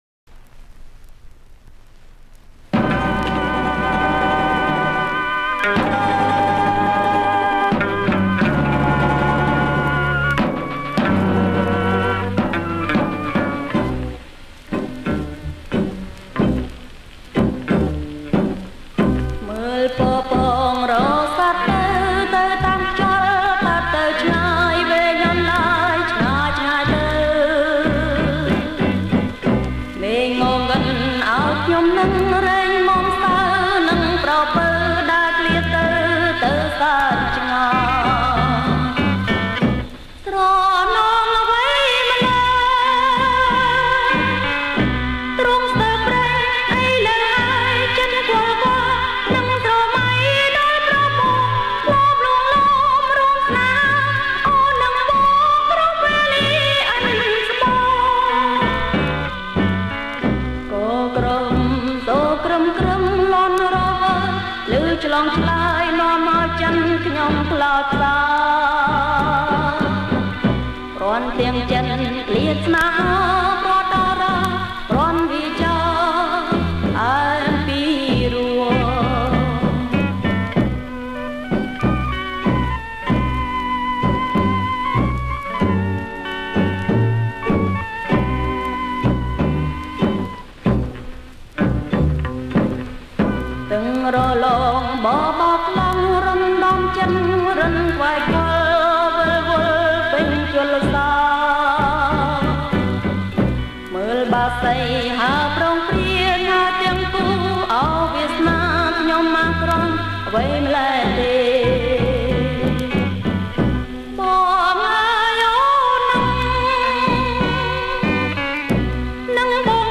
• ប្រគំជាចង្វាក់ Fox Medium